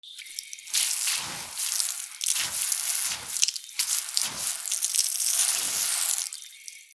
水を出す 屋外の蛇口 水が飛び散る
/ M｜他分類 / L30 ｜水音-その他